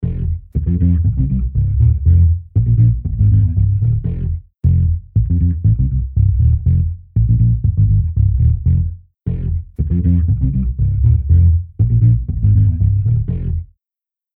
TriceraChorusは、サウンドに極上の豊かさと空間的な奥行きをもたらす、洗練されたコーラス・ソリューションです。
TriceraChorus | Bass | Preset: Paschorius
TriceraChorus-Eventide-Bass-Paschorius.mp3